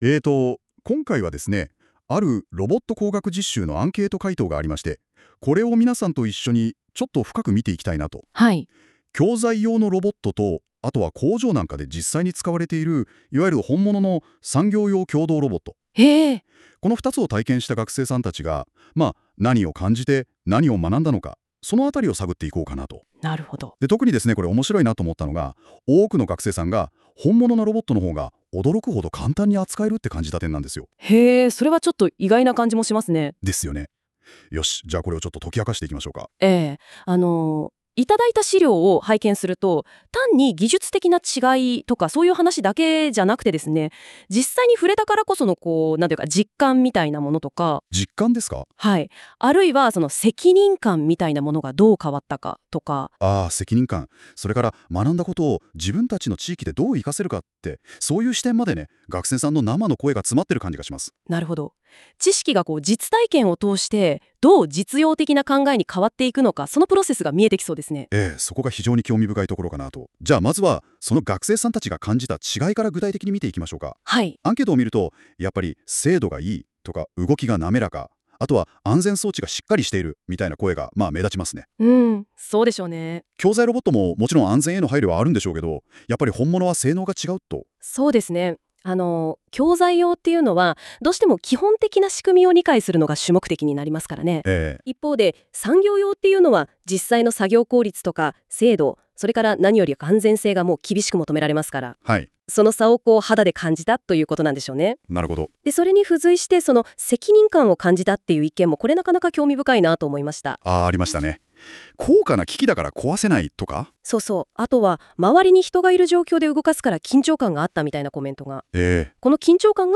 こちらから、AIによる「参加学生の感想」の要約をお聴きいただけます。